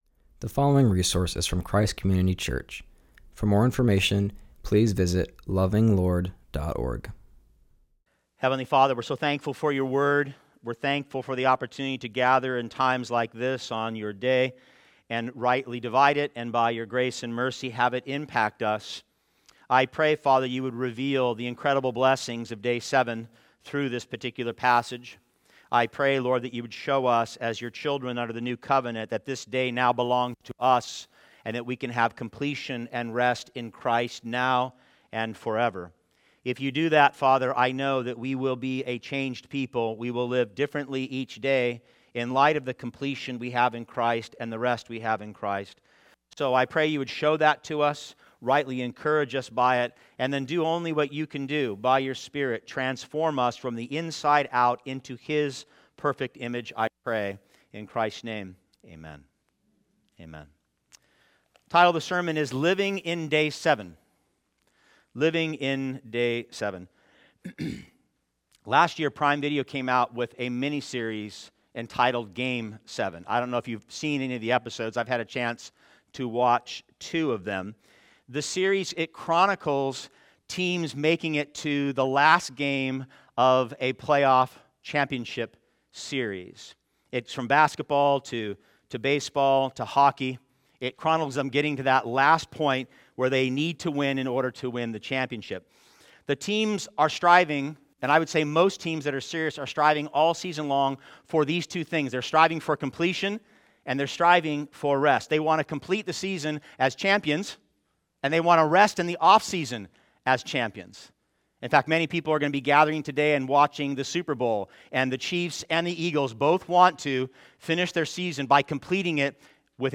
continues our series and preaches from Genesis 2:1-3